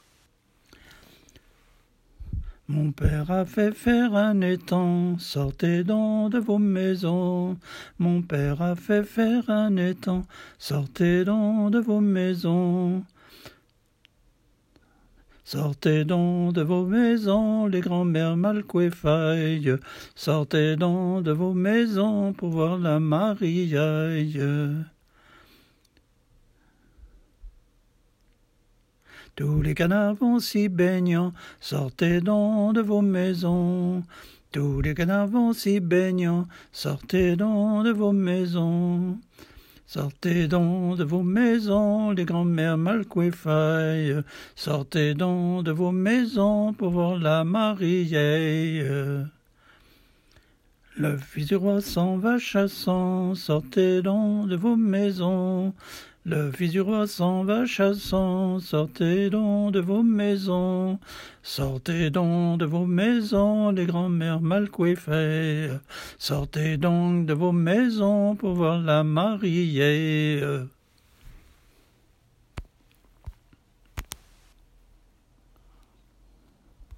chansons traditionnelles
Pièce musicale inédite